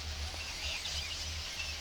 We had spent the day with the whales and recording in Boundary Pass.
As we were drifting K21 approached one of our hydrophones, stopped right in front of one, produced an S10 call, or as I like to say he was really excited to say hi or maybe he just wanted to speak his peace, and went on his way. Since he was right next to one of our hydrophones we got a great recording of it!